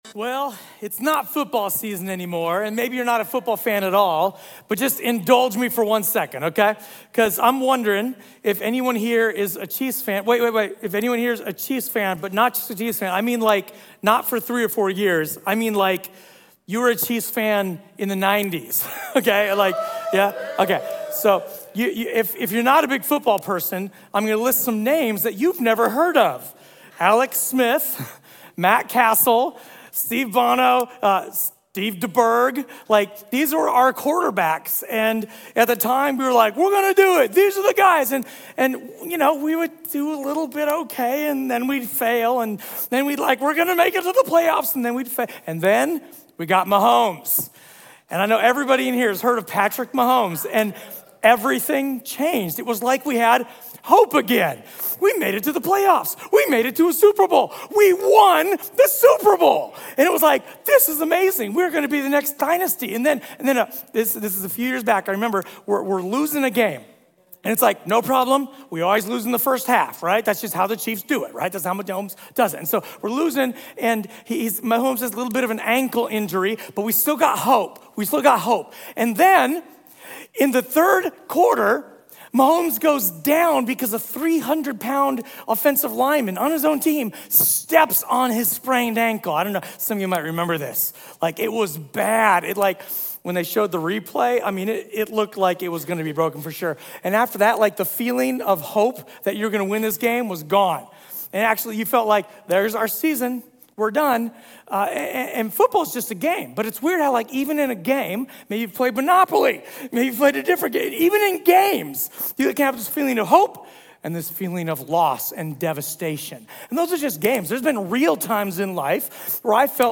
A sermon from the series "Guest." What does it mean to live as a model of faith, love, and hope?